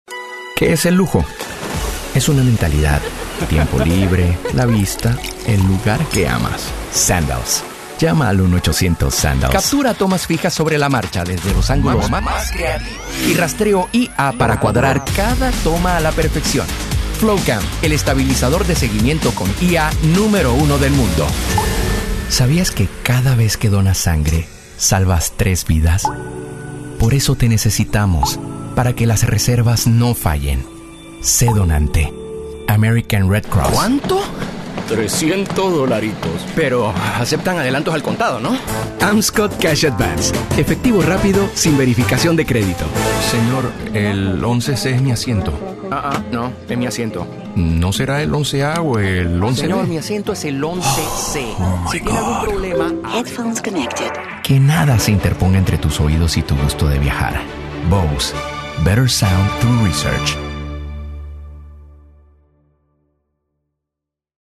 Experiencia comprobada en narración corporativa, documental, tutoriales, centrales telefónicas y virtualmente cualquier producto que necesite una grabación de voz. Certificado como técnico en producción de audio, con estudio profesional en casa.